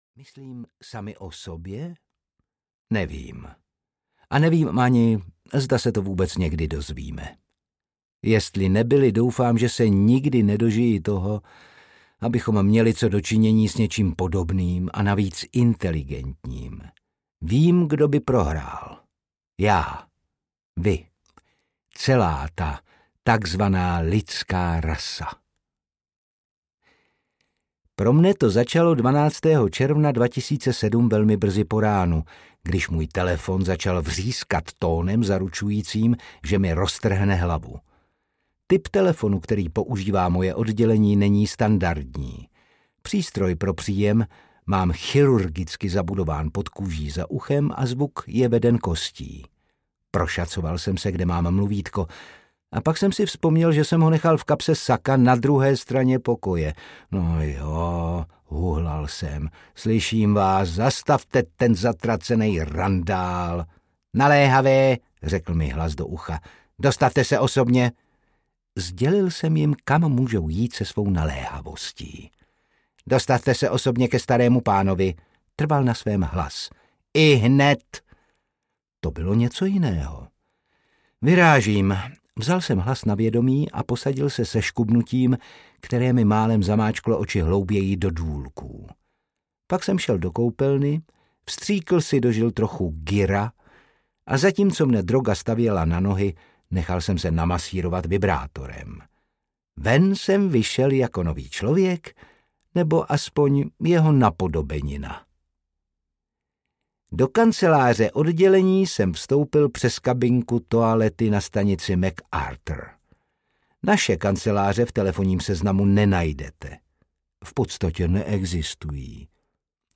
Vládci loutek audiokniha
Ukázka z knihy
• InterpretOtakar Brousek ml.